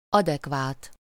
Ääntäminen
Ääntäminen France: IPA: /a.de.kwat/ Haettu sana löytyi näillä lähdekielillä: ranska Käännös Ääninäyte Adjektiivit 1. megfelelő 2. adekvát 3. alkamas Suku: f .